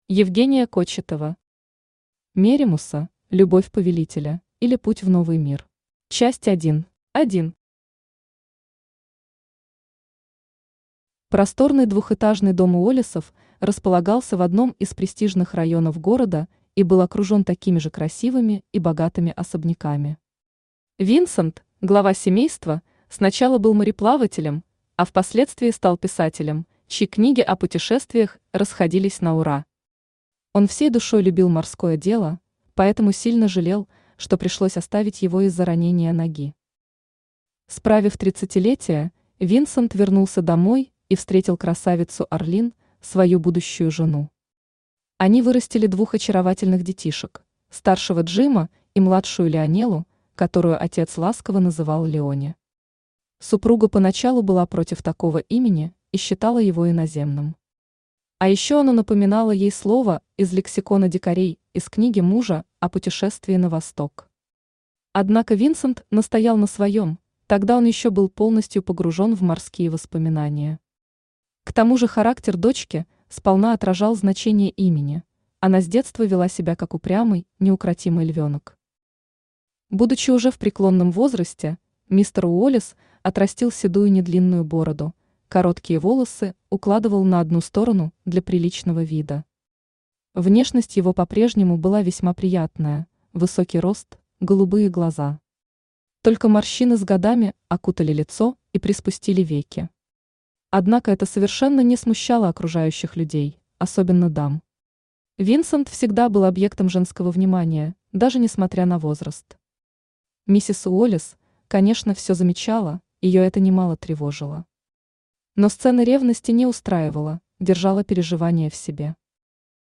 Aудиокнига Меримусса – любовь повелителя, или Путь в новый мир Автор Евгения Олеговна Кочетова Читает аудиокнигу Авточтец ЛитРес.